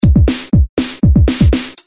The rendering of the above pattern with translation to the drumsample references below as made in the example, mpeg compressed is here (about 13kB):
drum1.mp3